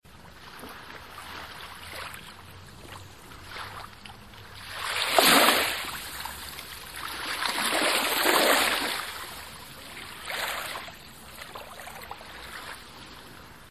Kostenlose Klingeltöne Sound Of Sea Waves